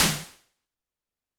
Drums_K4(41).wav